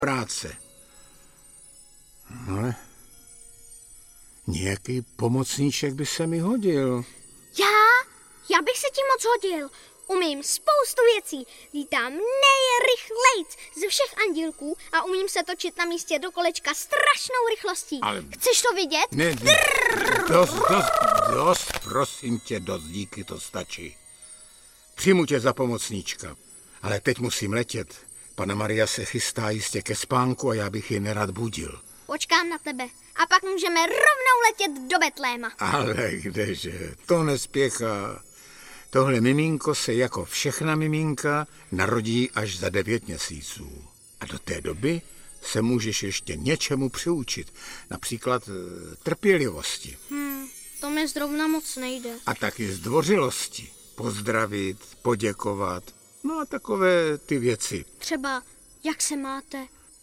Audiobook
Read: Jaromír Meduna